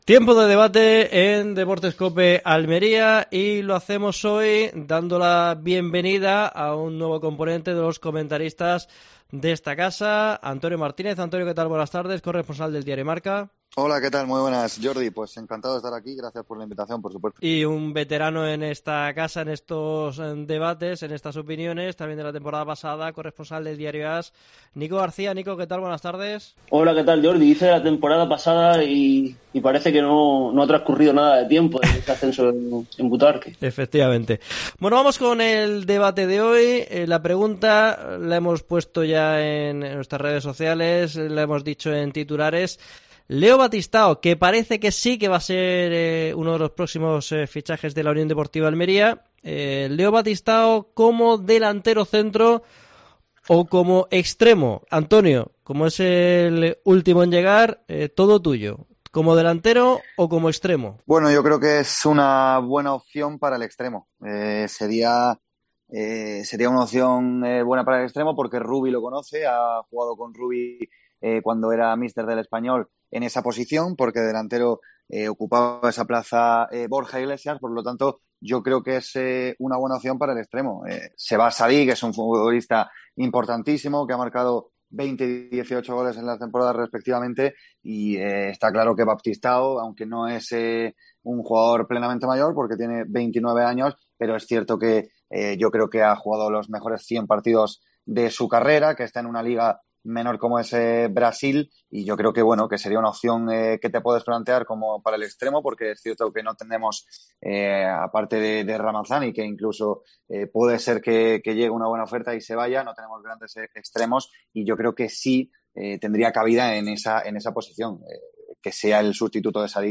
Debate Deportes COPE Almería. ¿Baptistao como sustituto de Sadiq?